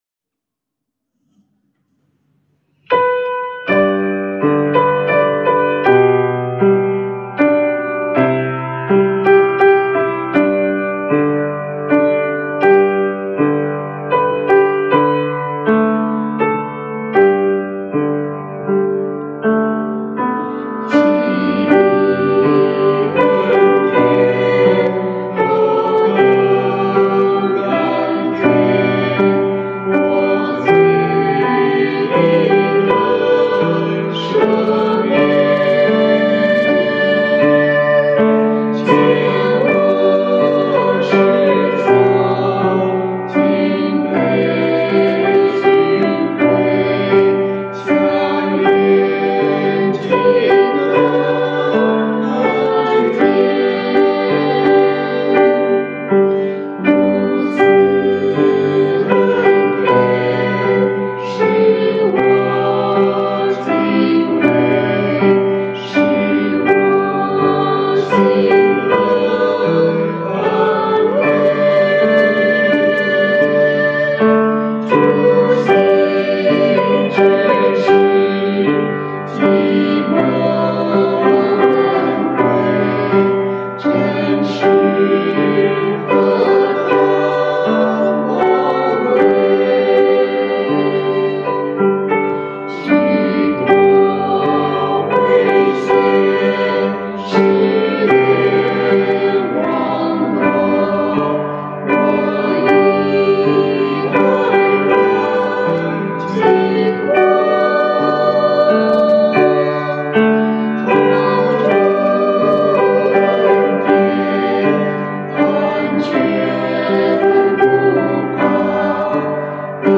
赞美诗 | 奇异恩典